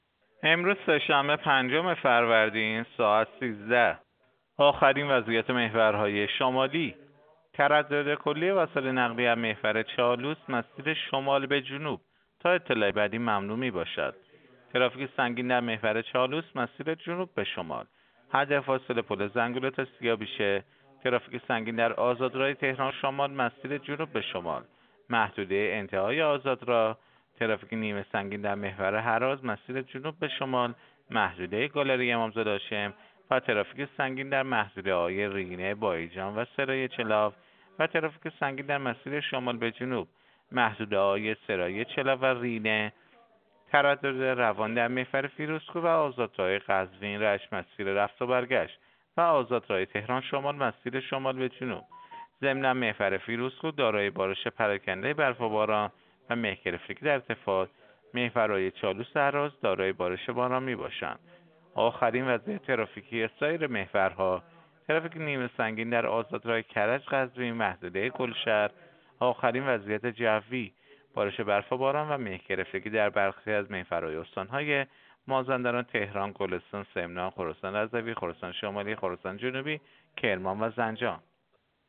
گزارش رادیو اینترنتی از آخرین وضعیت ترافیکی جاده‌ها ساعت ۱۳ پنجم فروردین؛